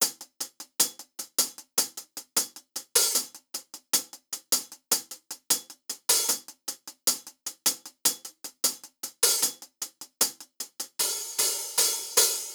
Closed Hats
Beholder 2 Live Hi Hat.wav